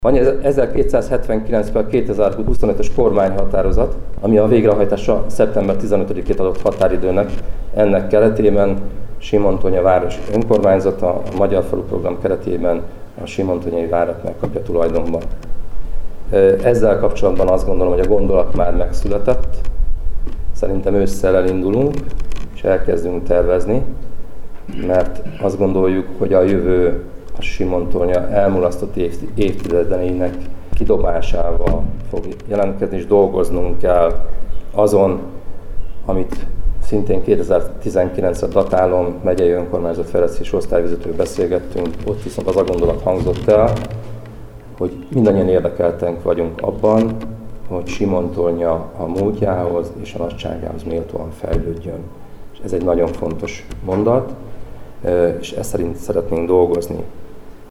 Torma József, Simontornya polgármestere szintén beszédet mondott az ünnepségen. A városvezető nemcsak a projekt technikai részleteiről számolt be a megjelenteknek, hanem kiemelten fontosnak tartja a város történelmi múltját is, mely jó alapot szolgáltat a jövőre vonatkozóan is.